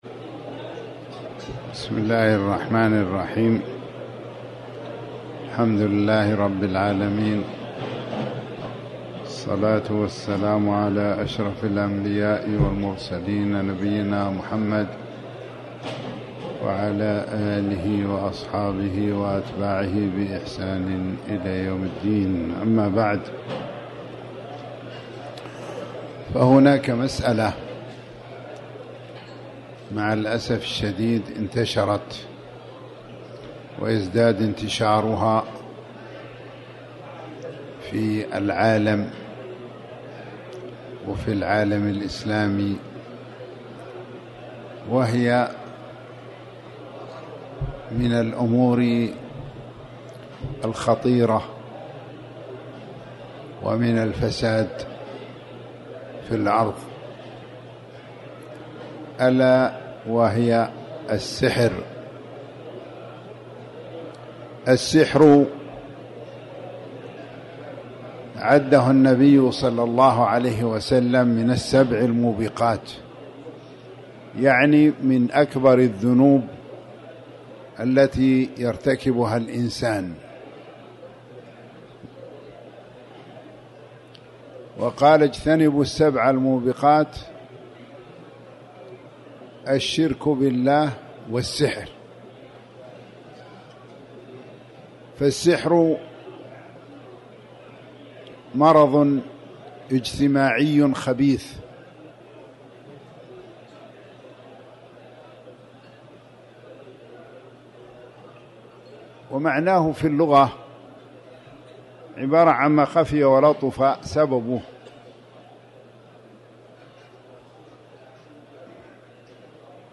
تاريخ النشر ٢٥ رمضان ١٤٤٠ هـ المكان: المسجد الحرام الشيخ